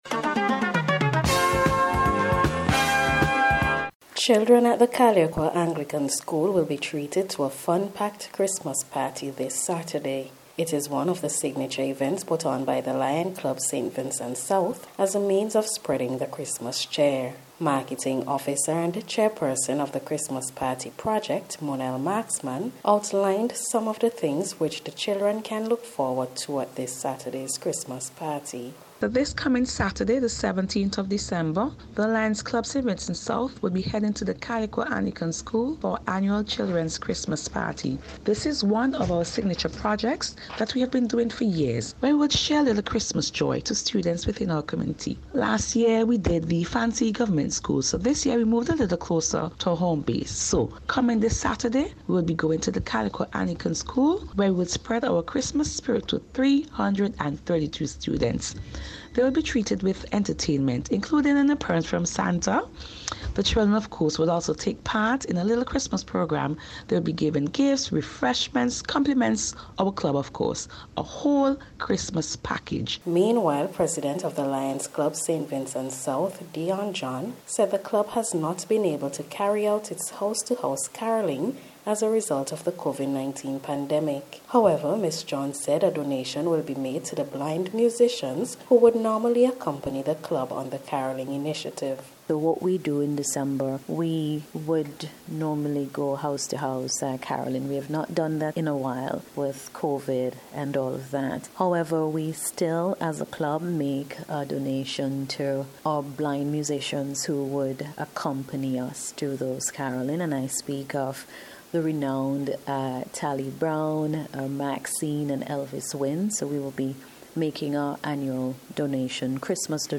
NBC’s Special Report – Thursday December 15th 2022